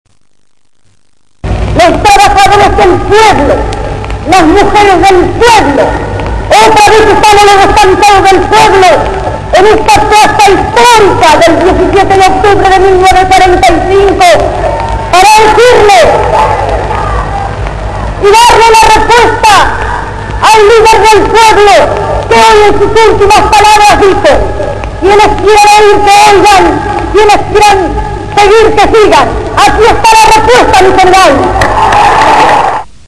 voz_evita.mp3